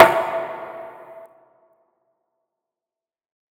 TM88 8V8 Snare.wav